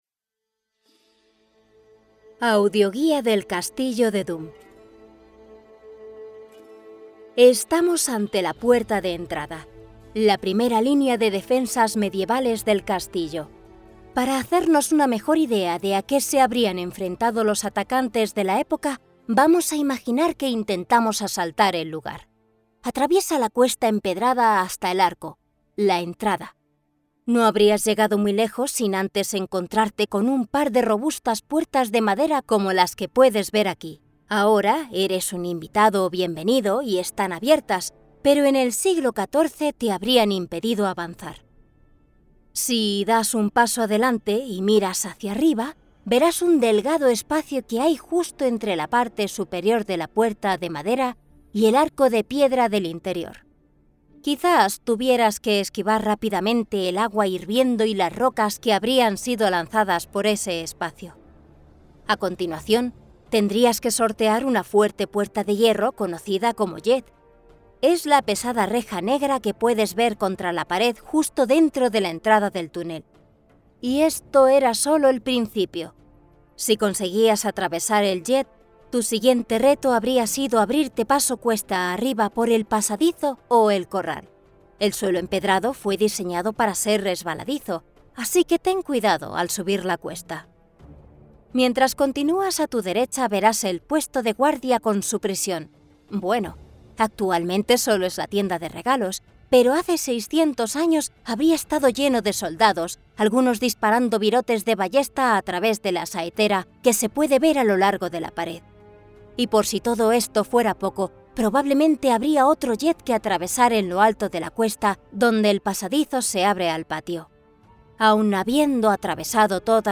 Commercial, Young, Natural, Versatile, Soft
Audio guide